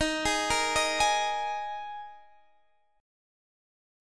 Ebsus4 chord